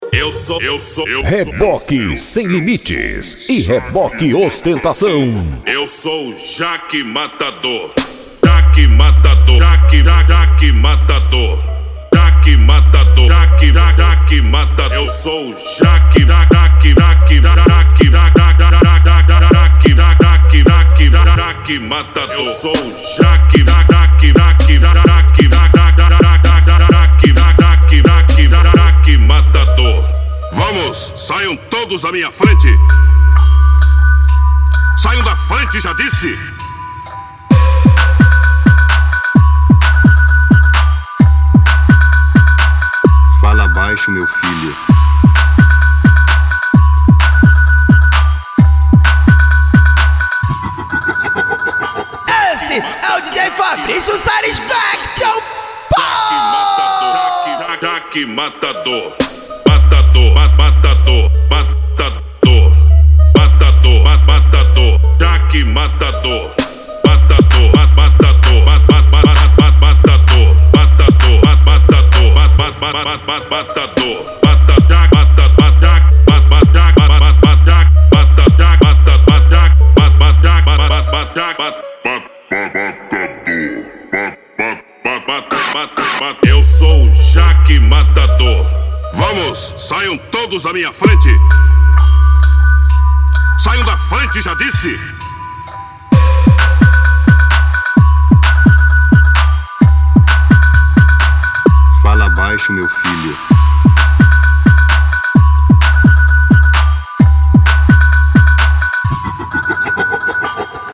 Bass
Funk
Mega Funk
Remix